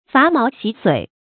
伐毛洗髓 注音： ㄈㄚˊ ㄇㄠˊ ㄒㄧˇ ㄙㄨㄟˇ 讀音讀法： 意思解釋： 刮去毛發，洗清骨髓。